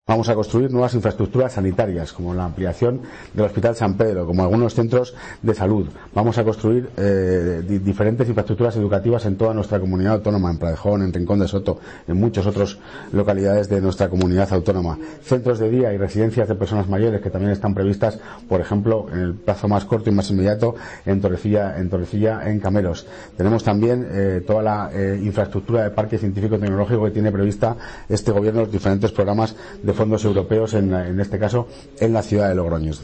El consejero de Hacienda y Gobernanza Pública, Alfonso Domínguez, explica la ejecución de los fondos